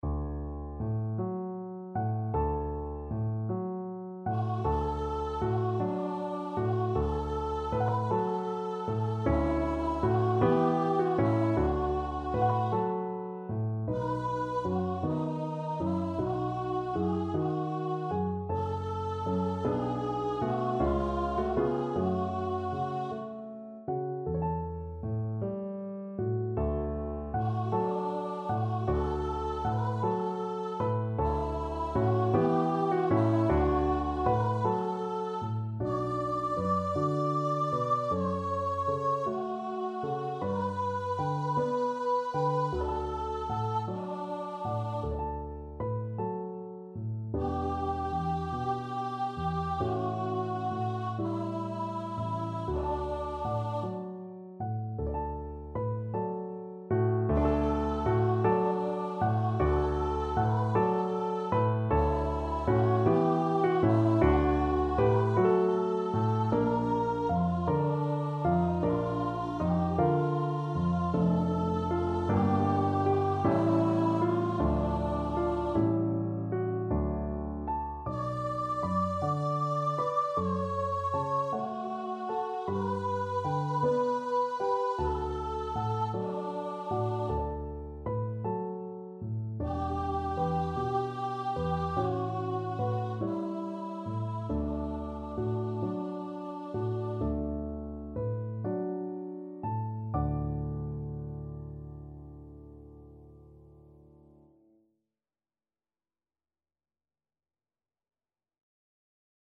Free Sheet music for Voice
D major (Sounding Pitch) (View more D major Music for Voice )
~. = 52 Allegretto
6/8 (View more 6/8 Music)
D5-D6
Classical (View more Classical Voice Music)